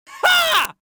Wild Laughs Male 02
Wild Laughs Male 02.wav